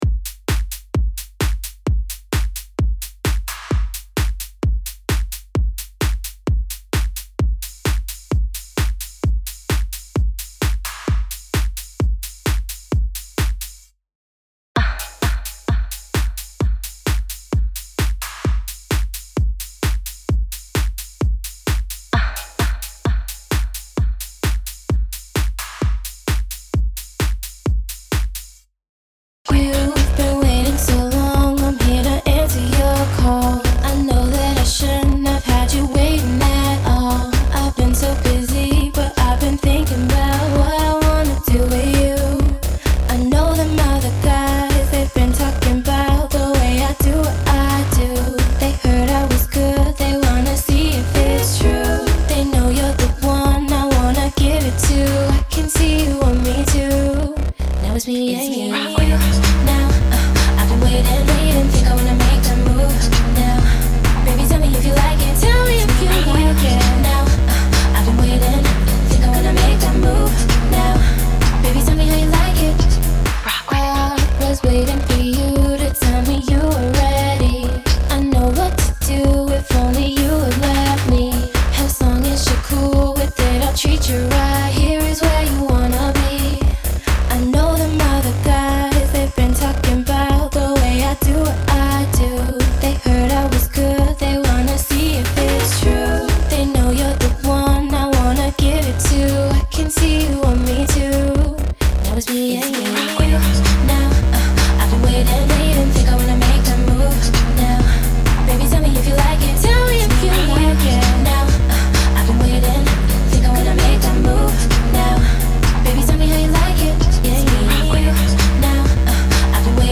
FUTURE RAVEFUTURE HOUSE